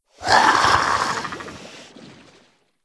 c_seasnake_bat1.wav